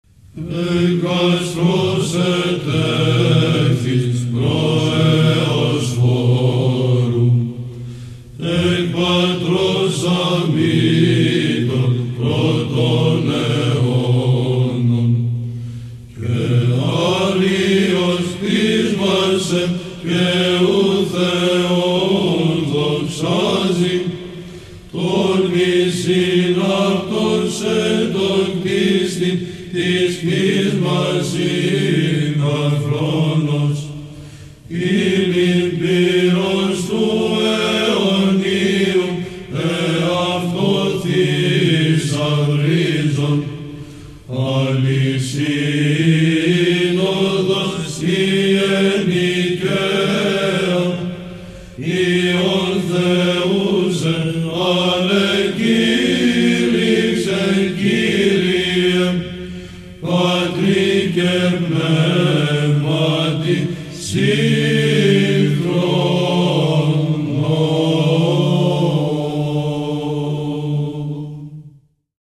Ήχος πλ. β'